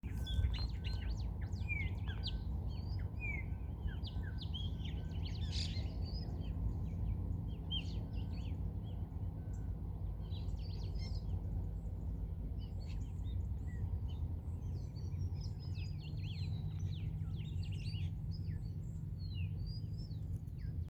Chopi Blackbird (Gnorimopsar chopi)
Location or protected area: Parque Nacional Mburucuyá
Condition: Wild
Certainty: Photographed, Recorded vocal